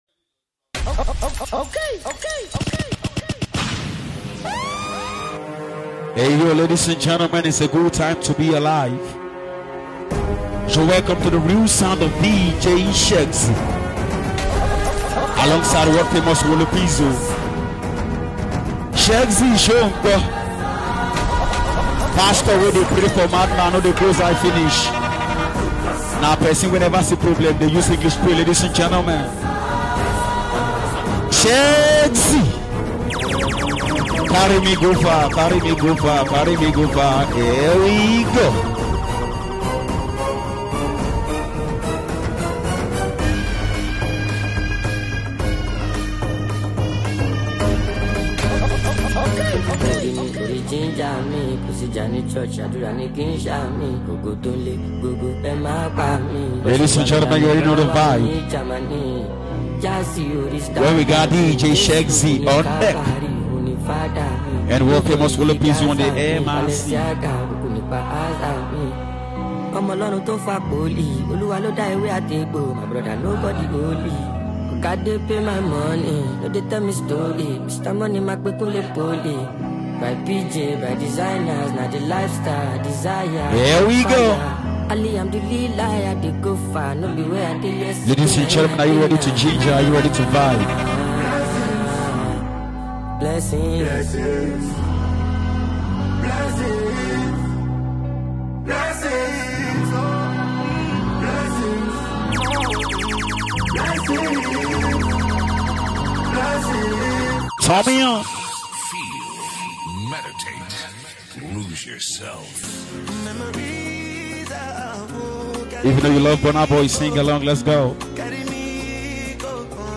The most anticipated mixtape you’ve been waiting for is here
cool vibes
funny jokes